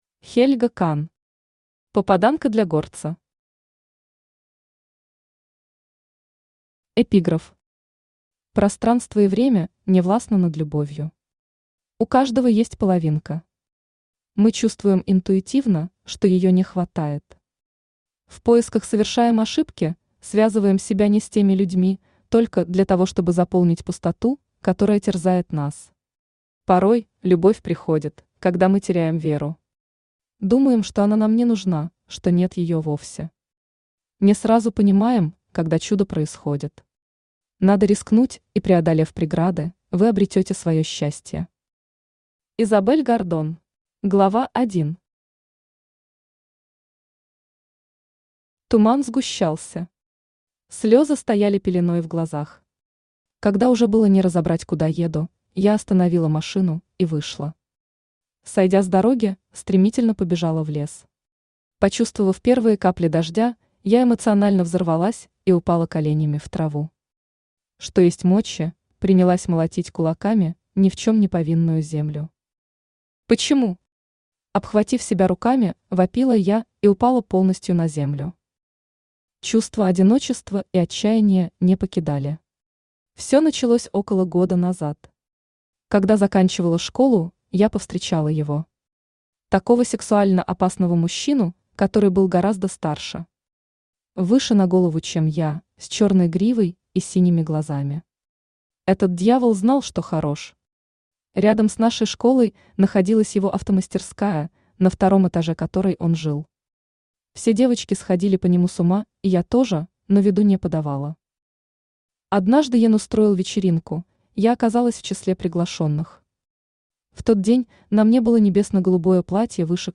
Aудиокнига Попаданка для горца Автор Хельга Кан Читает аудиокнигу Авточтец ЛитРес.